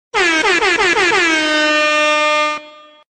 Europa Plus Звук сирены